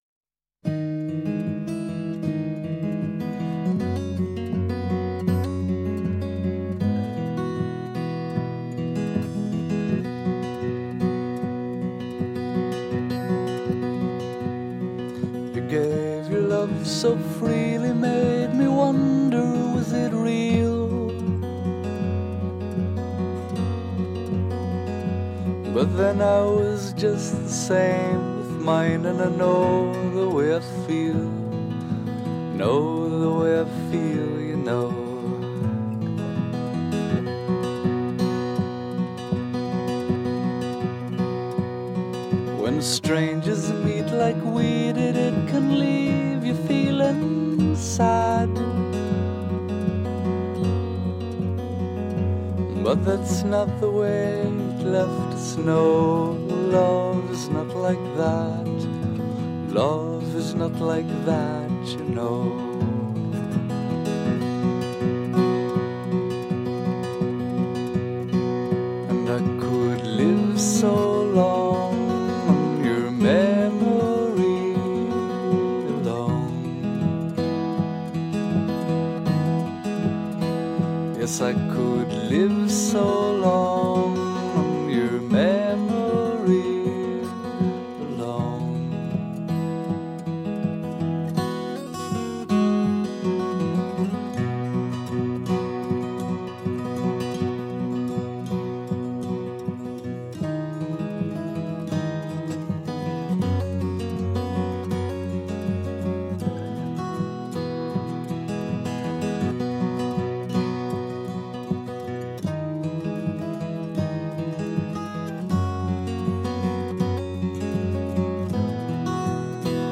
I’ve never heard of this great duo, but I want to hear more.